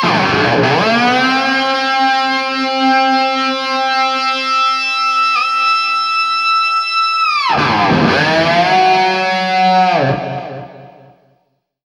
DIVEBOMB14-R.wav